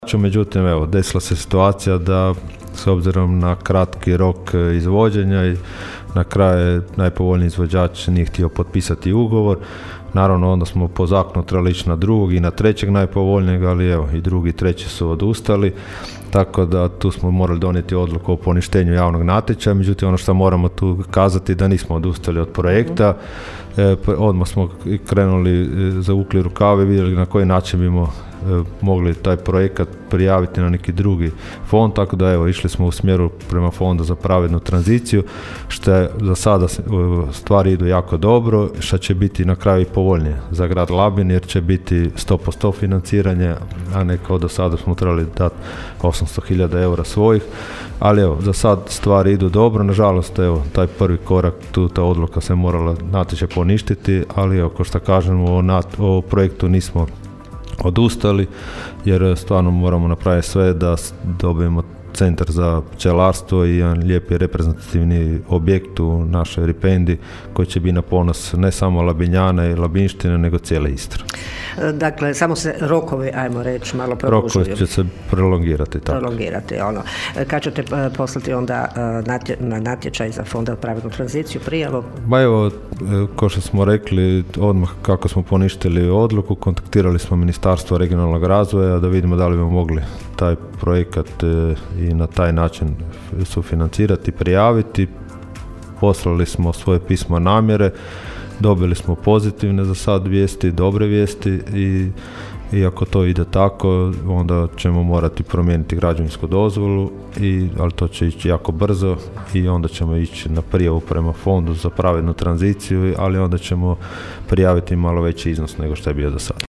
ton – Donald Blašković), pojasnio je labinski gradonačelnik Donald Blašković.